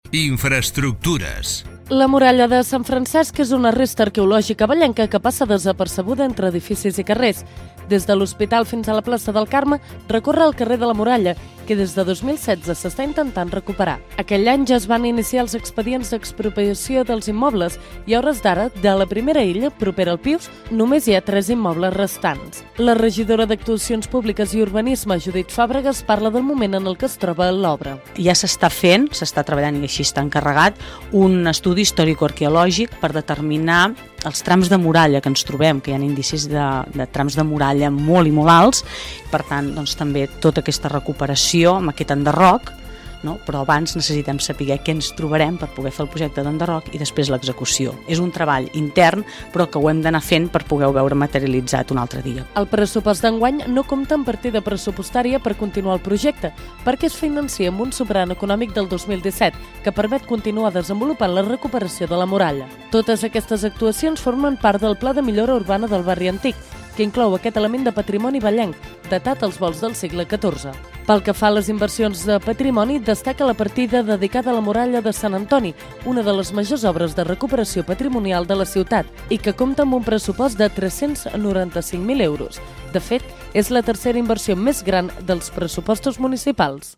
Segons la regidoria d’Actuacions Públiques i Urbanisme, Judit Fàbregas, parla del moment en el que es troba actualment l’obra.